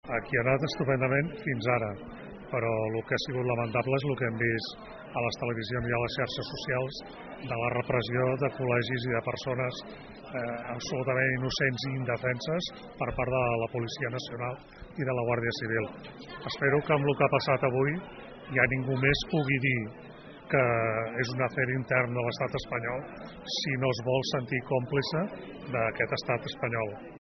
Al final de la jornada, els votants valoraven als micròfons d’aquesta emissora com havia anat la jornada de votacions a Palafolls, en alguns casos amb sorpresa per la participació, i també denunciaven la repressió policial.